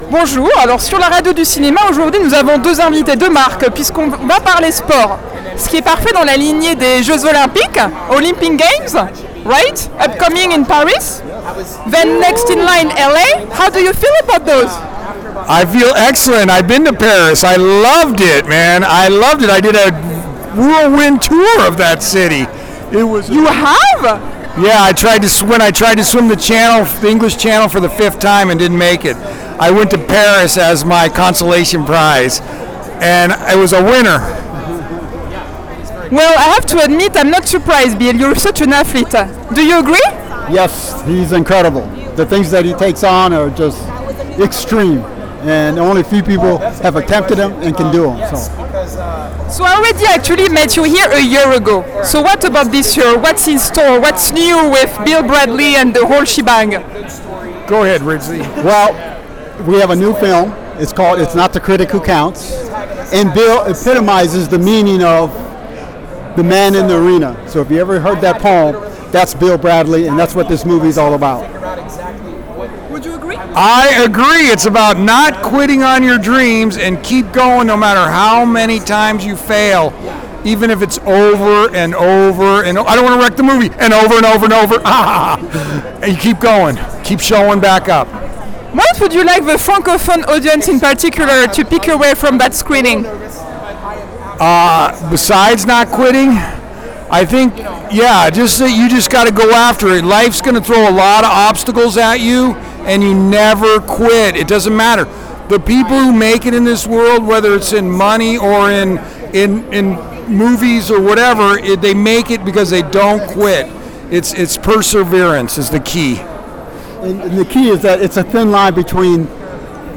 à l'occasion du HollyShorts Film Festival 2023